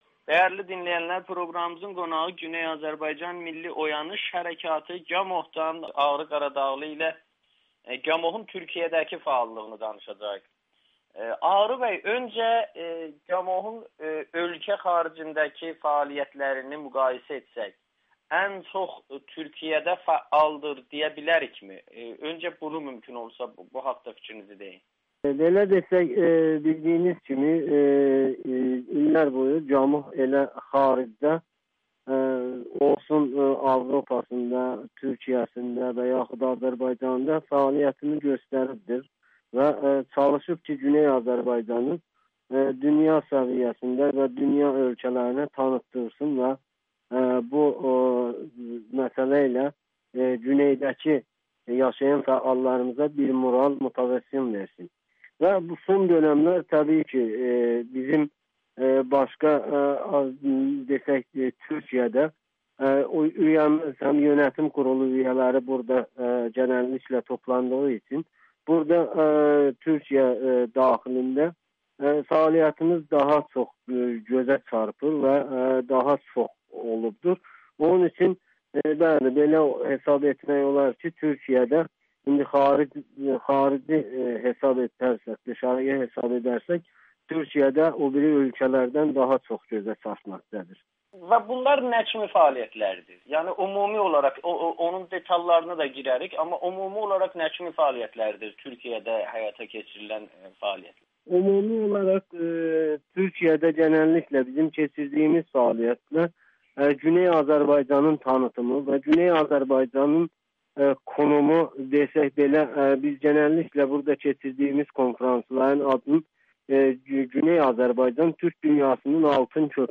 Hazırda GAMOH-un ən çox fəal olduğu ölkə Türkiyədir [Audio-Müsahibə]